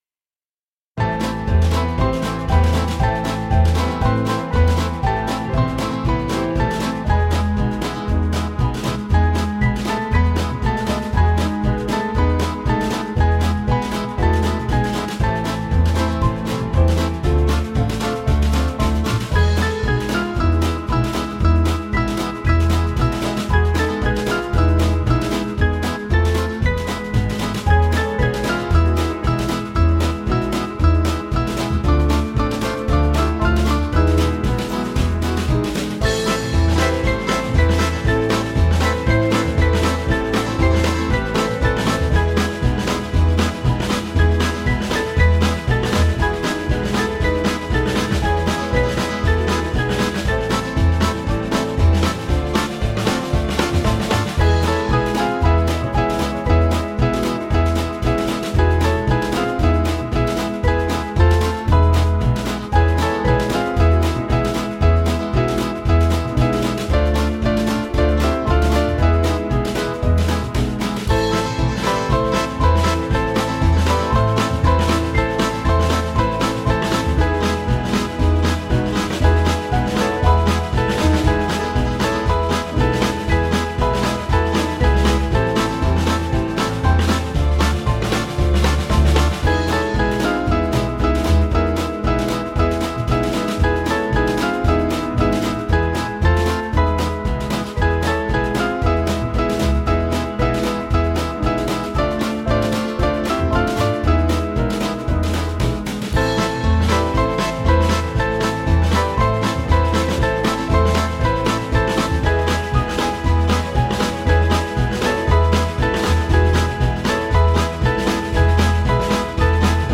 Small Band
(CM)   3/Am